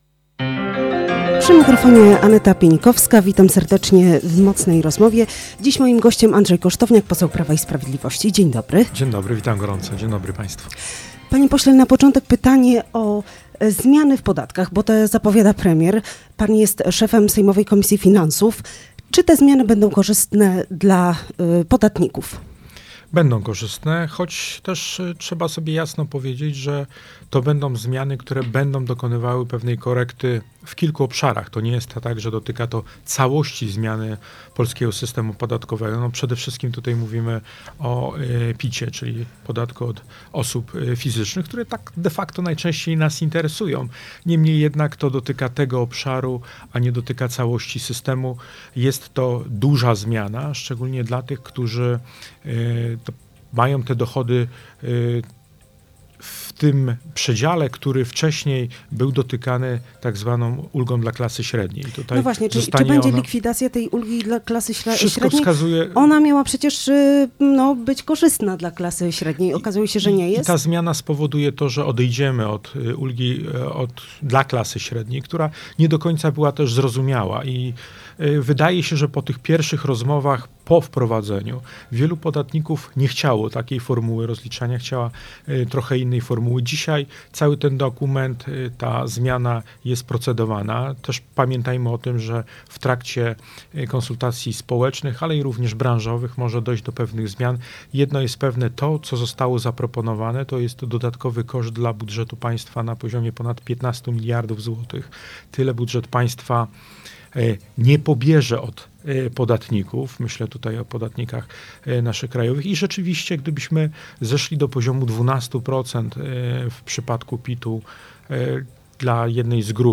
Poseł Prawa i Sprawiedliwości Andrzej Kosztowniak był gościem